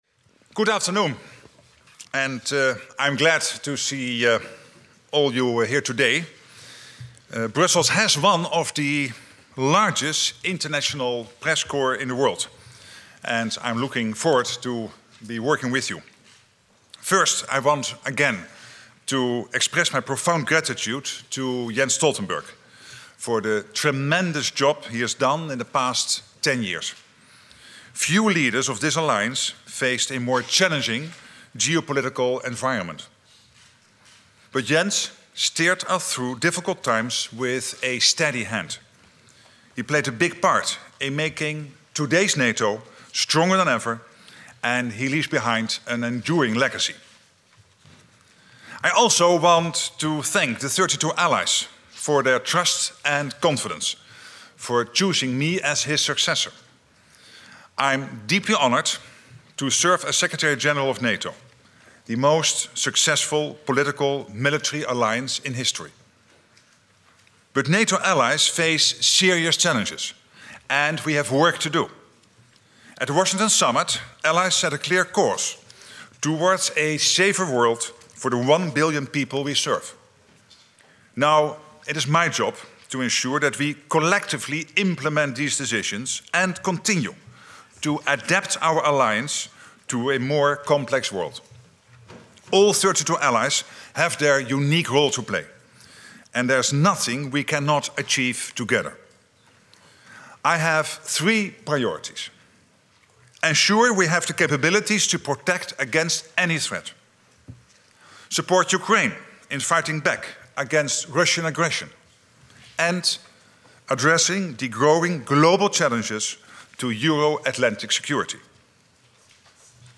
Opening Statement at Inaugural NATO Press Conference as Secretary General
delivered 1 October 2024, NATO HQ, Brussels, Belgium
Audio Note: AR-XE = American Rhetoric Extreme Enhancement